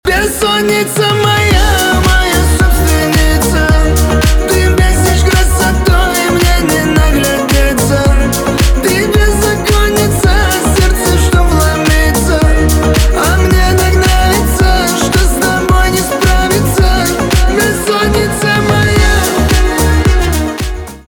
поп
битовые басы